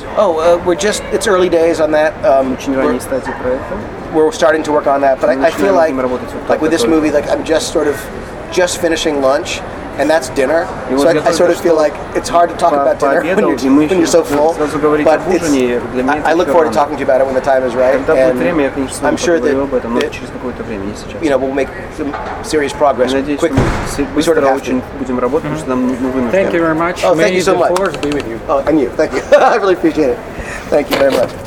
Режиссер фантастического фильма "Стартрек: Возмездие (Звездный путь 2)" Джей Джей Абрамс в эксклюзивном интервью ответил на вопросы портала "Новости Кино".
Я сидел с ним за столиком в ресторане , а рядом сидел переводчик:) Мне он был не нужен, я просто хотел, чтобы синхронный превод был сразу, чтобы не накладывать его потом при монтаже:) Но вышлоне очень удачно, так как было очень шумно вокруг.
Просто иногда голос Абрамса почему-то заглушает голос переводчика, из-за чего трудновато слушать) Спасибо за нестандартное интервью и за то, что задали мой вопрос про Алькатрас)